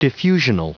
Prononciation du mot diffusional en anglais (fichier audio)
diffusional.wav